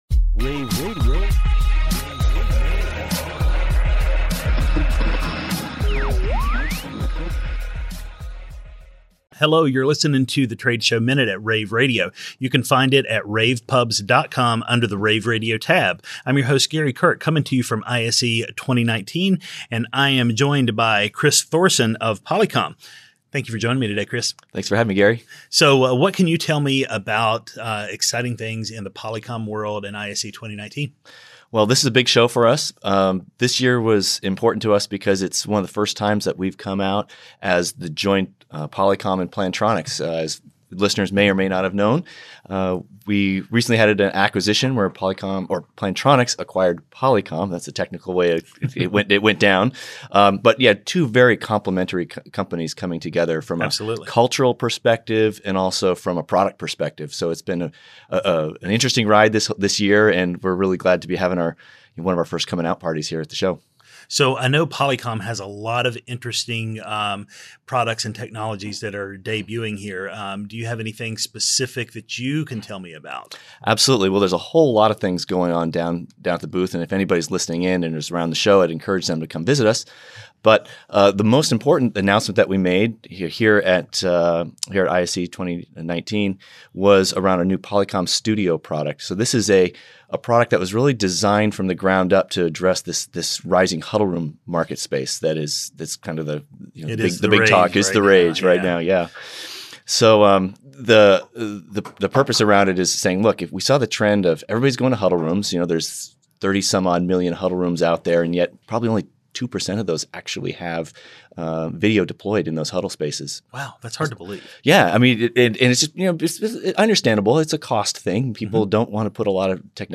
interviews
February 6, 2019 - ISE, ISE Radio, Radio, rAVe [PUBS], The Trade Show Minute,